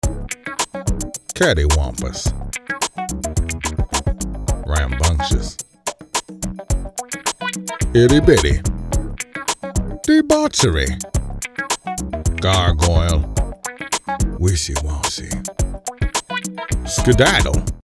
I present to you, Morgan Freeman saying funky words.